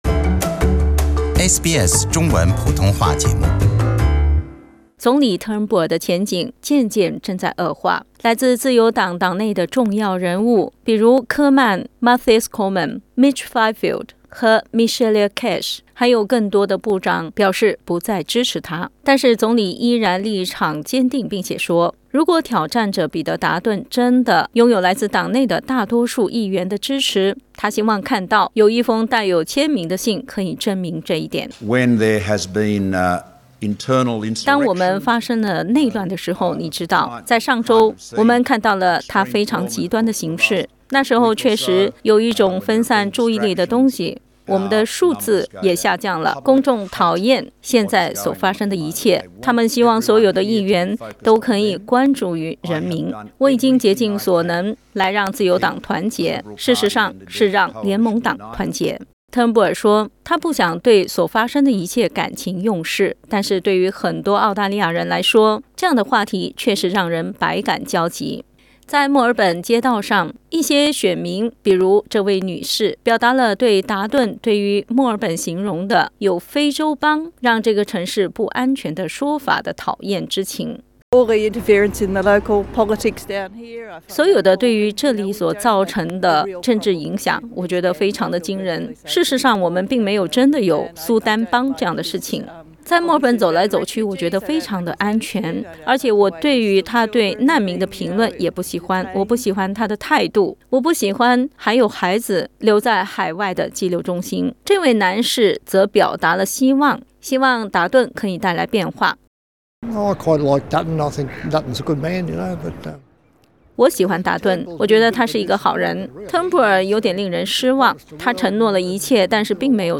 街头采访：自由党内斗街头民众反应“不安”